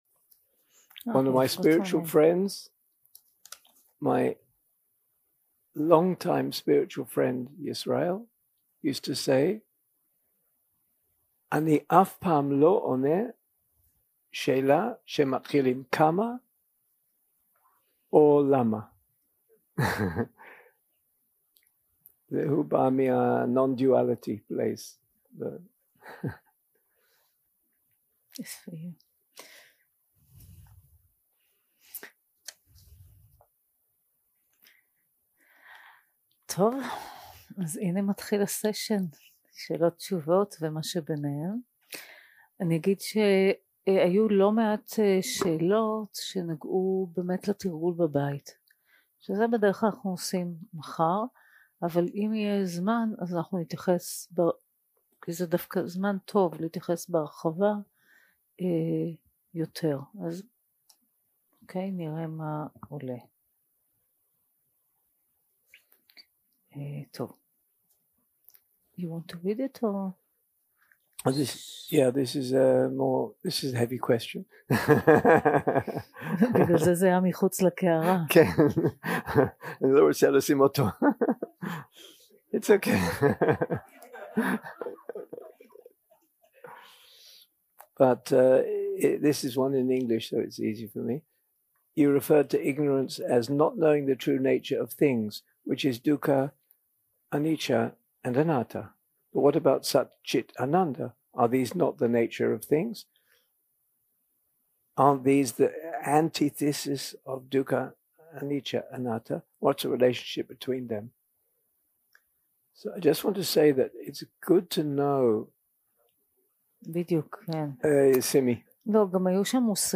יום 7 – הקלטה 19 – ערב – שאלות ותשובות
Dharma type: Questions and Answers שפת ההקלטה